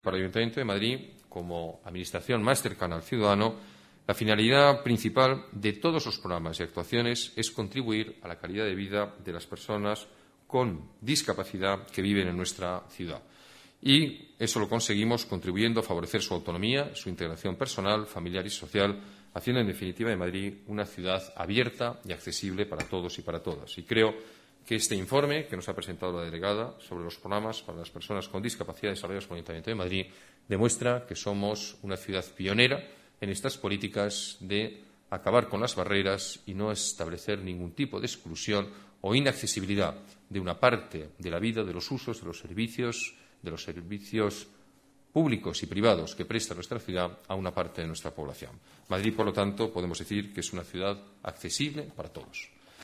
Nueva ventana:Declaraciones del alcalde sobre los programas municipales para discapacitados